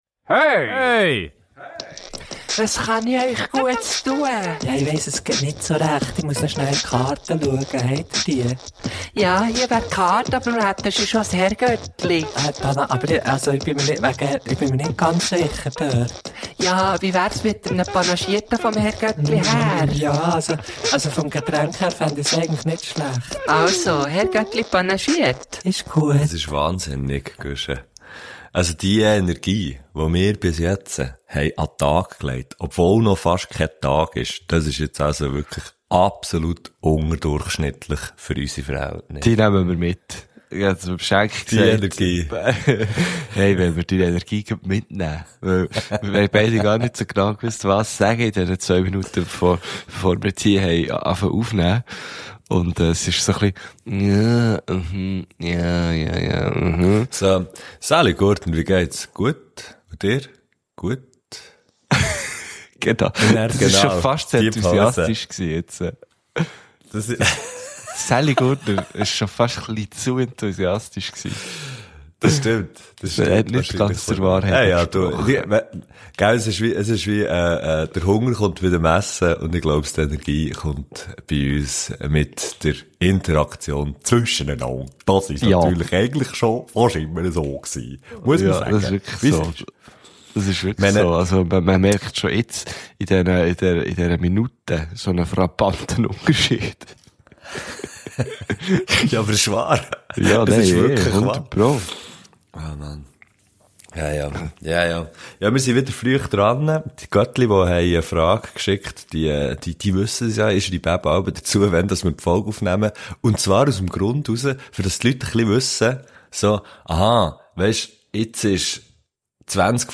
Auch wenn wir am Anfang der Folge noch nicht so klingen. Das liegt ausschliesslich an der Uhrzeit.
Wir sind quais während des Aufnehmens erwacht und sind dann ganz Ende der Folge aber sowas von on point.